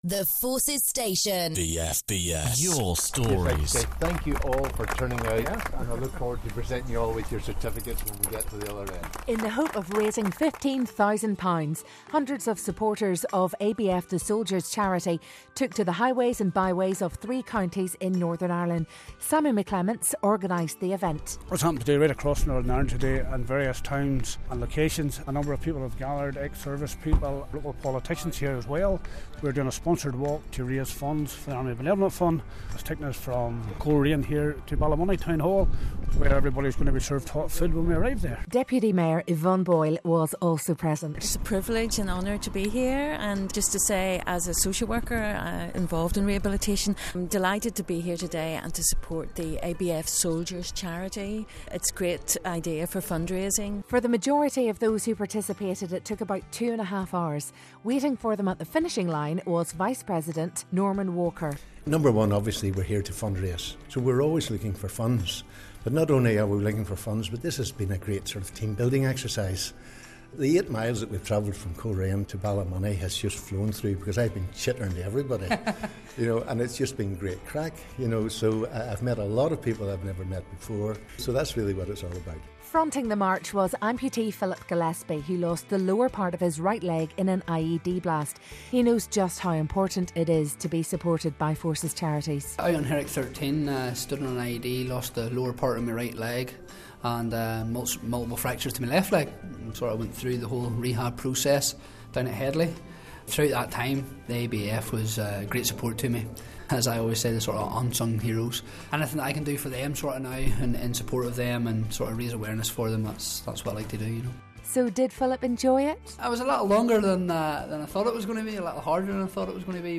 Raising money for ABF The Soldier's Charity, scores of people gathered in Coleraine to march 7.8 miles to the Town Hall in Ballymoney